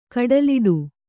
♪ kaḍaliḍu